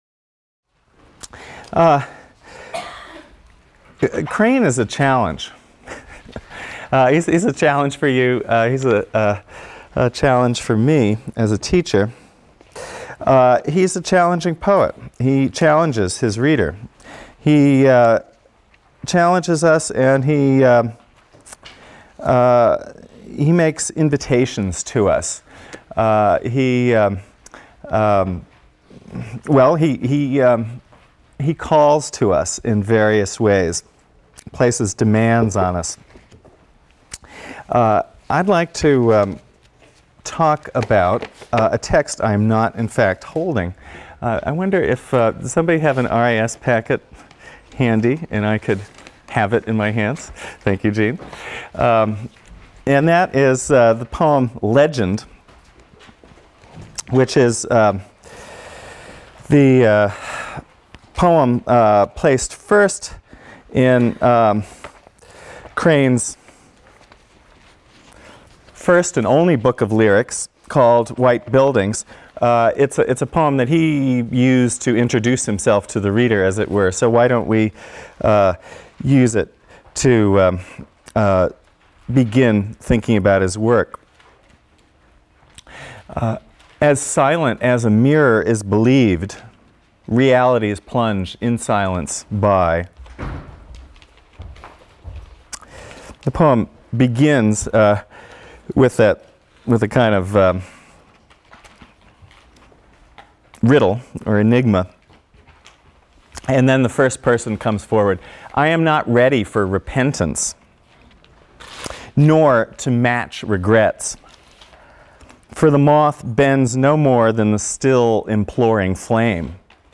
ENGL 310 - Lecture 13 - Hart Crane | Open Yale Courses